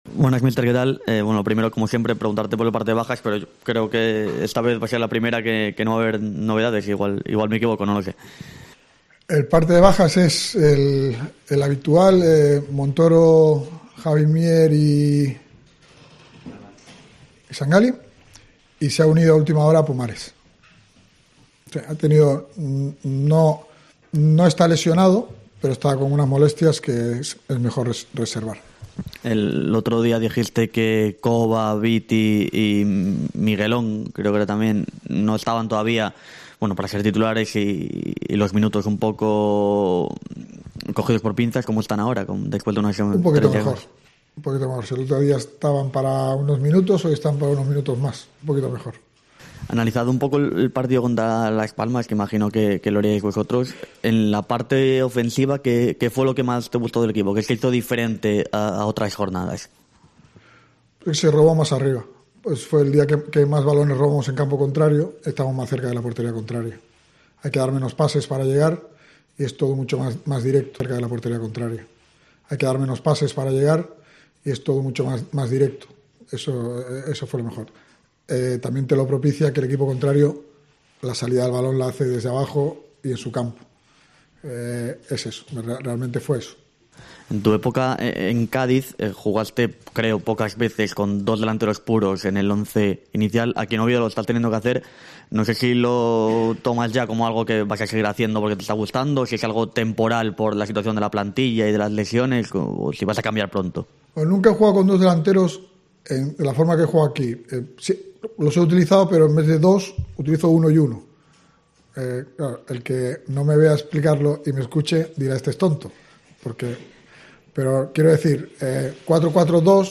Rueda de prensa Cervera (previa Eibar)